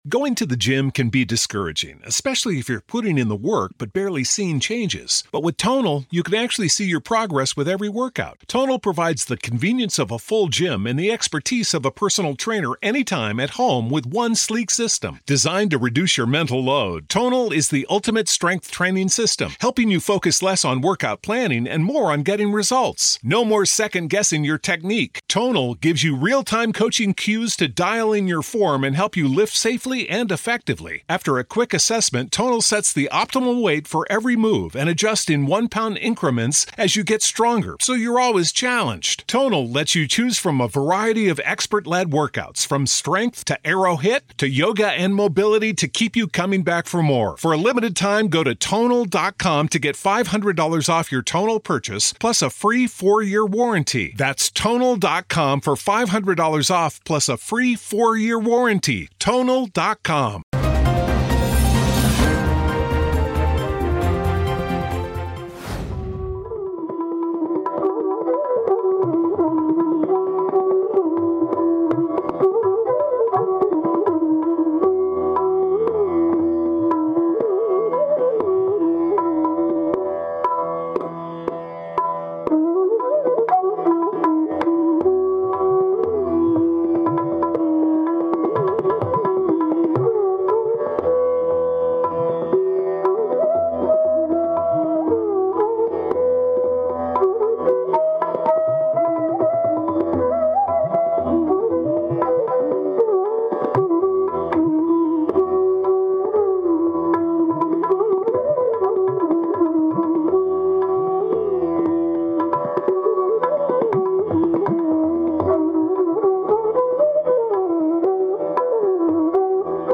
न्यूज़ रिपोर्ट - News Report Hindi / इंदिरा गांधी की पुण्यतिथि आज, कठोर फैसले लेने वाली विनम्र महिला